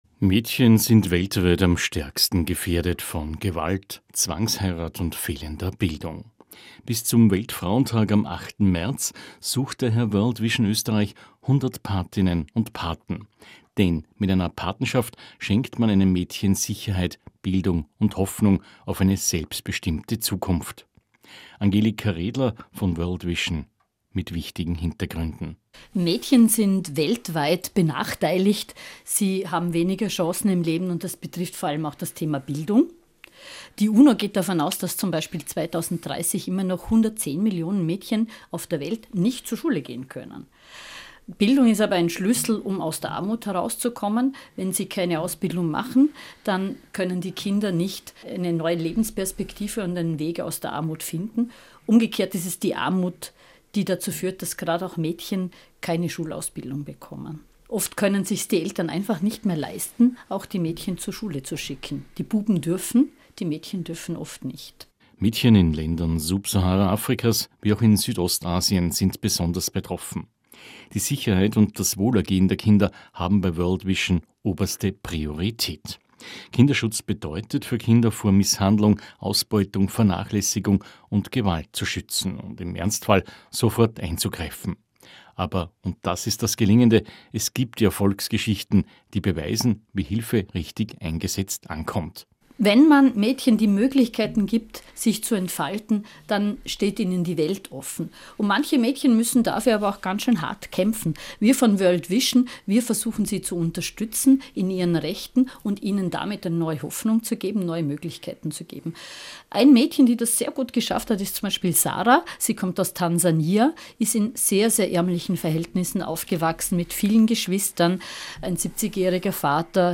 Radiobeiträge zu unserer „100 Mädchen“ Kampagne